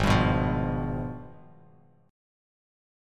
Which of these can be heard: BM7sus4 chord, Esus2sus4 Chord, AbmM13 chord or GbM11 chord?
AbmM13 chord